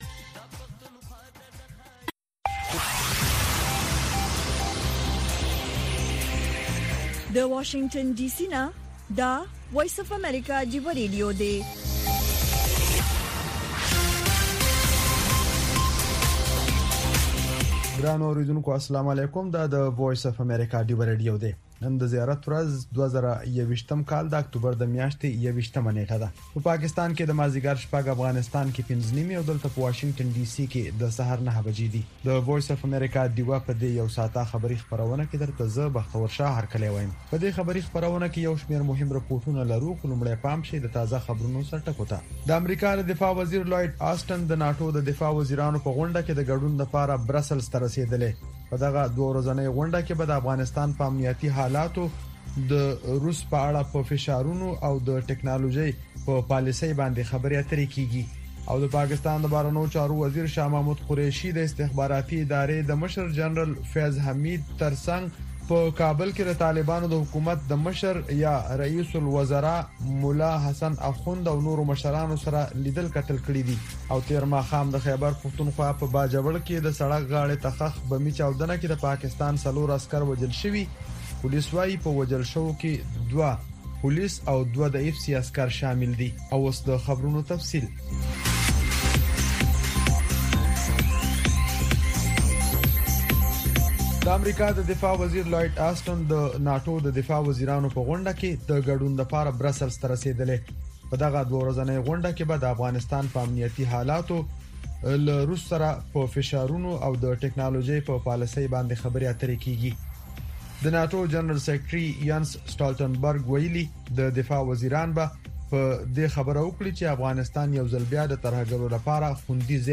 د وی او اې ډيوه راډيو ماښامنۍ خبرونه چالان کړئ اؤ د ورځې د مهمو تازه خبرونو سرليکونه واورئ.